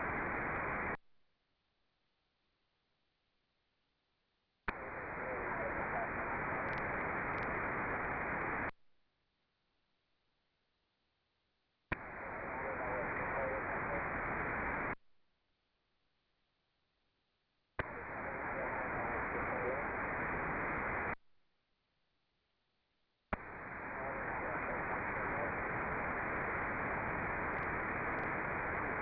Echoes from some strong stations and from my station.
My own SSB echoes, listen carefully 😎